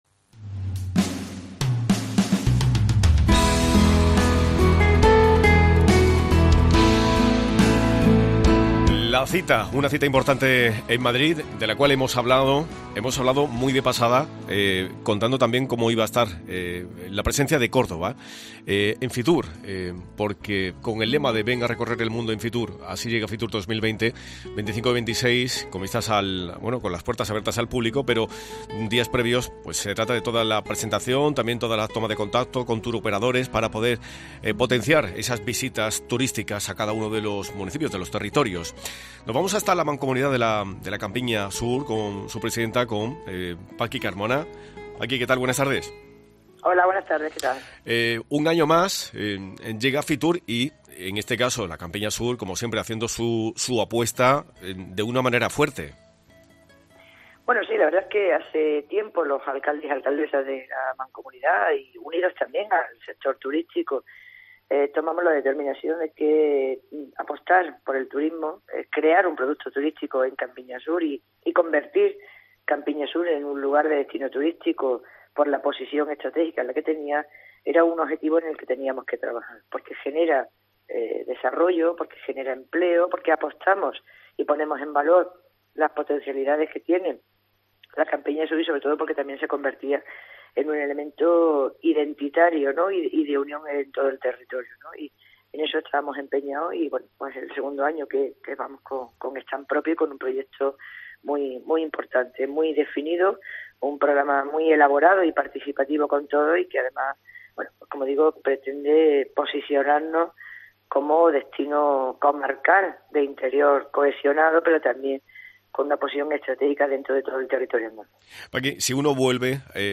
Francisca Carmona, presidenta de la Mancomunidad de la Campiña Sur Cordobesa sobre la presencia en Fitur 2020